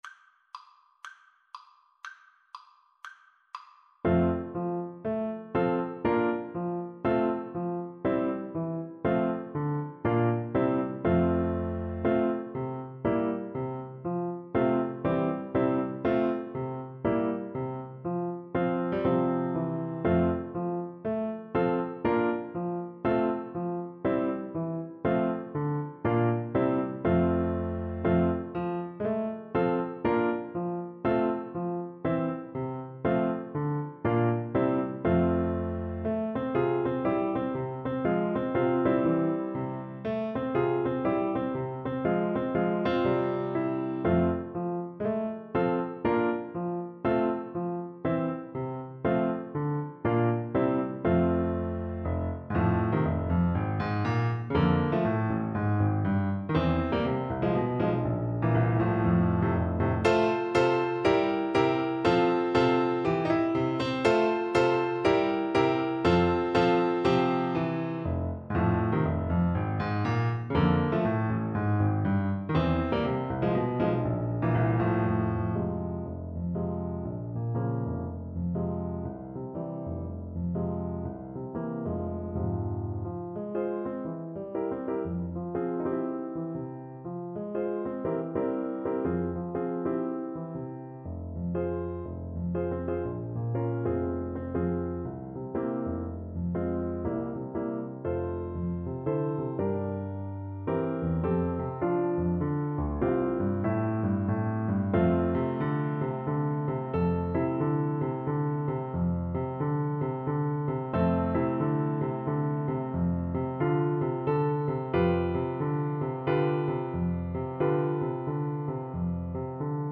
blues, funk, country and boogie-woogie.
2/4 (View more 2/4 Music)
THEME: Allegro =c.120 (View more music marked Allegro)
Jazz (View more Jazz Clarinet Music)
Rock and pop (View more Rock and pop Clarinet Music)